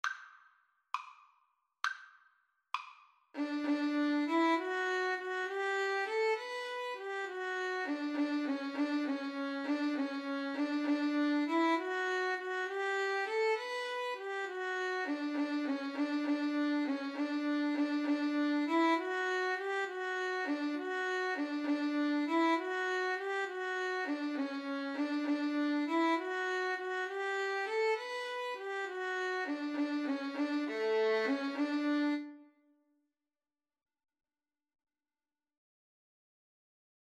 6/8 (View more 6/8 Music)
Violin Duet  (View more Easy Violin Duet Music)